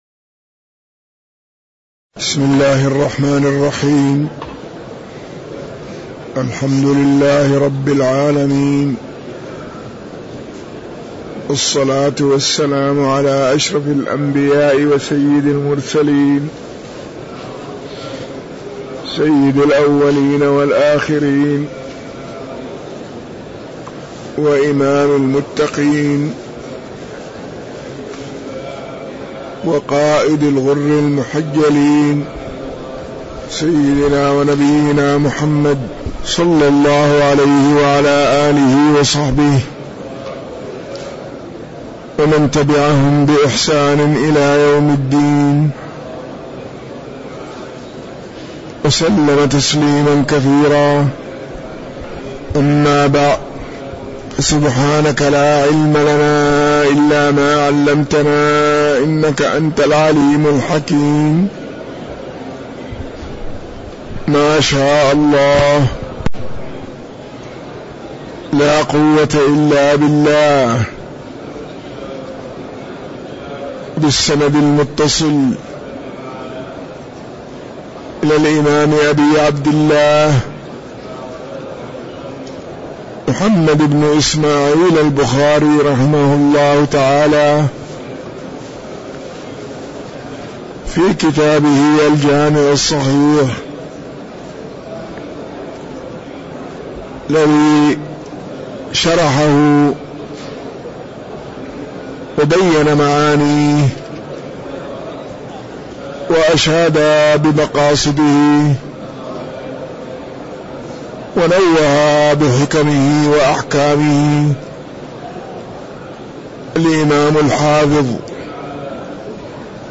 تاريخ النشر ٩ شوال ١٤٤٣ هـ المكان: المسجد النبوي الشيخ